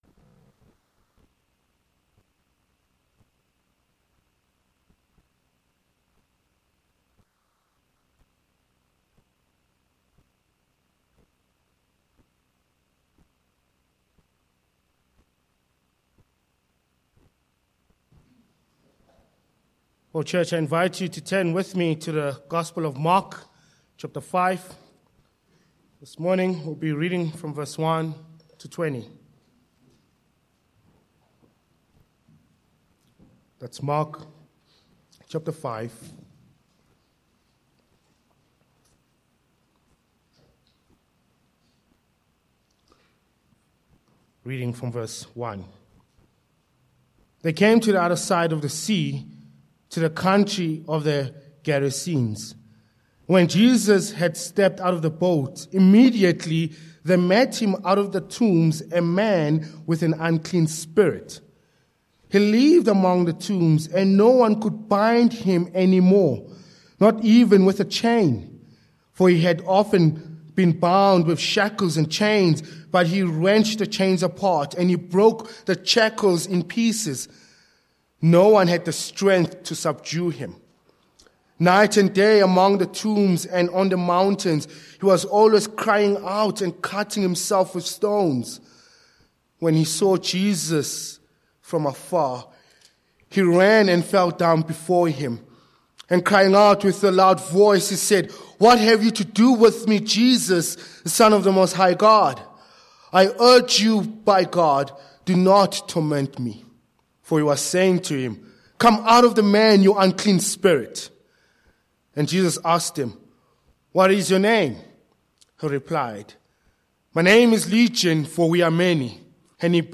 Sermon points: 1. Setting the Scene v1-5